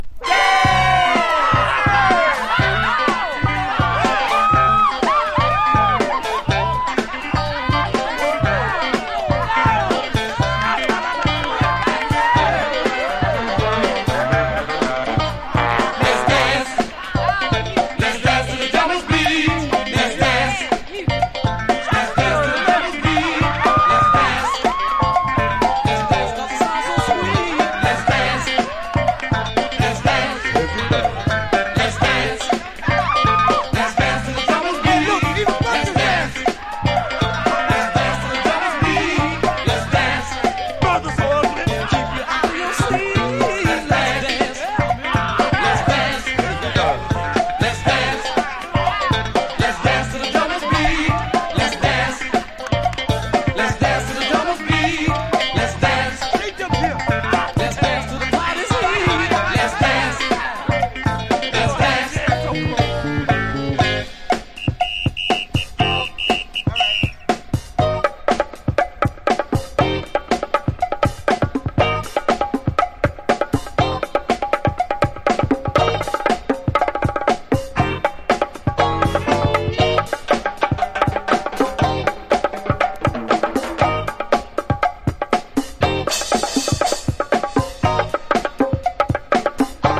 # RARE GROOVE# FUNK / DEEP FUNK# DISCO